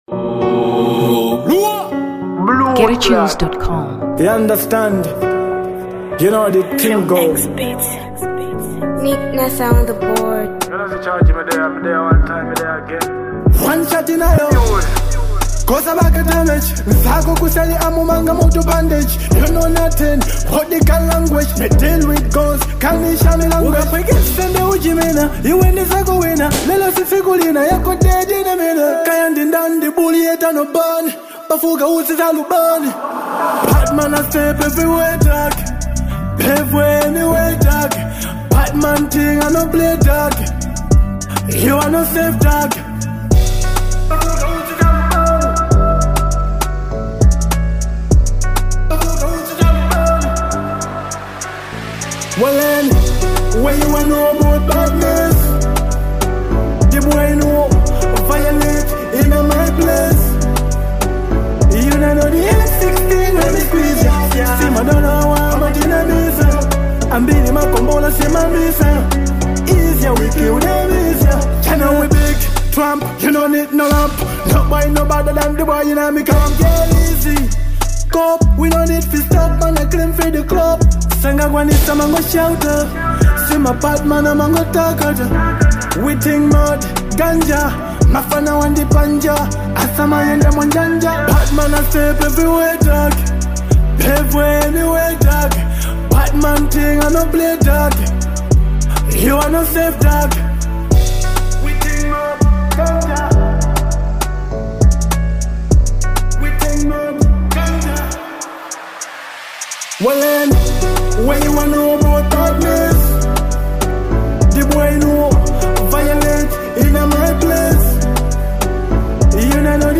Dancehall 2023 Malawi